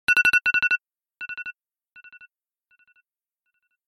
เสียงเรียกเข้าไอโฟน Original เสียงนาฬิกาปลุกดังๆ
หมวดหมู่: เสียงเรียกเข้า